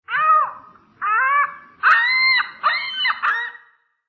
На этой странице вы найдете подборку звуков страуса – от характерного шипения до необычных гортанных криков.
Крик испуганного страуса